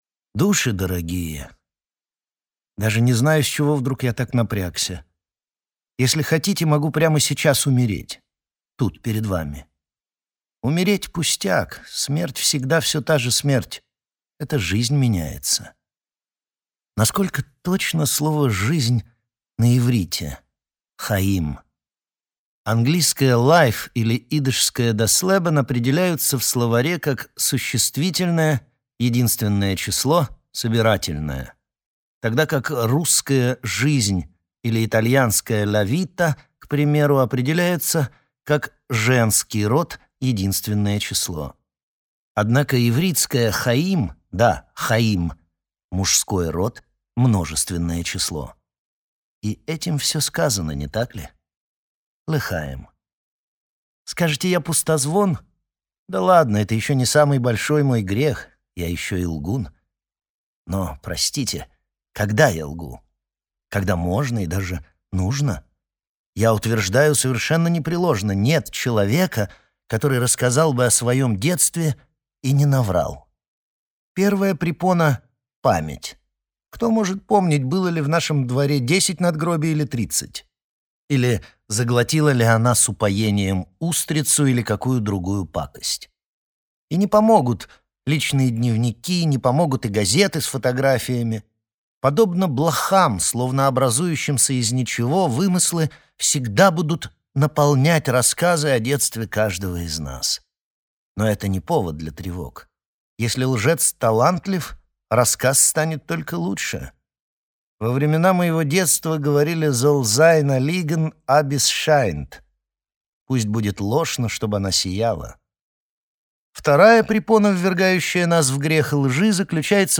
Aудиокнига Души Автор Рои Хен Читает аудиокнигу Анна Каменкова.